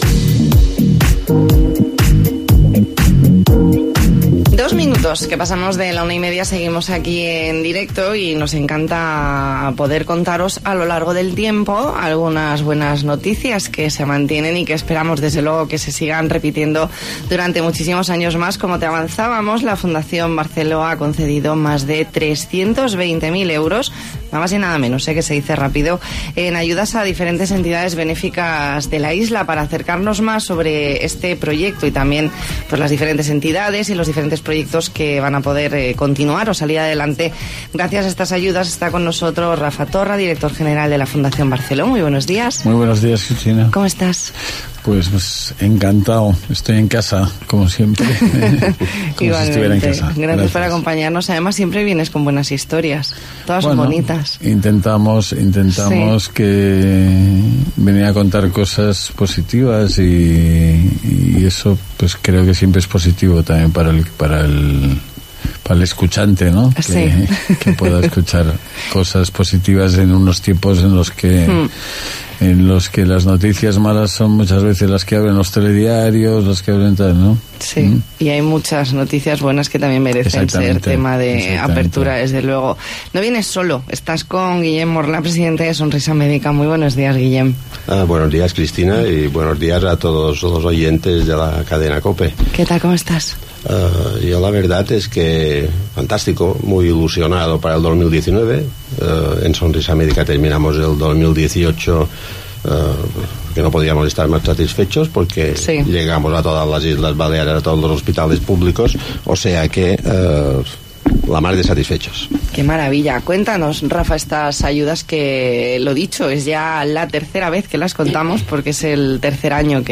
Entrevista en 'La Mañana en COPE Más Mallorca', martes 5 de febrero de 2019.